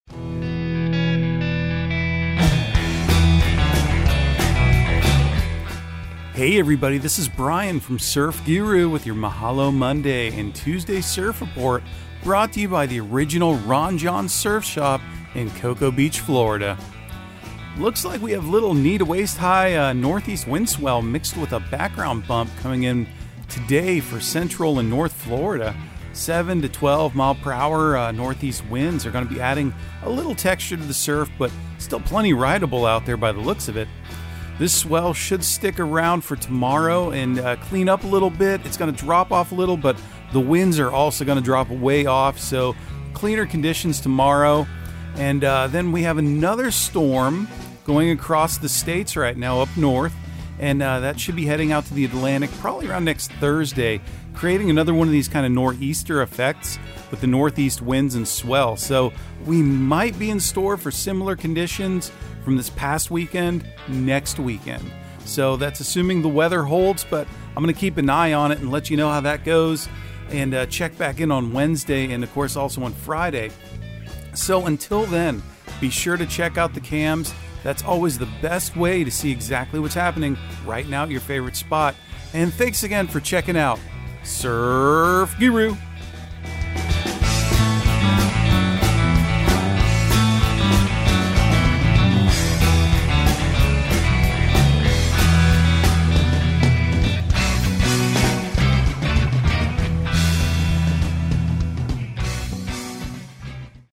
Surf Guru Surf Report and Forecast 10/24/2022 Audio surf report and surf forecast on October 24 for Central Florida and the Southeast.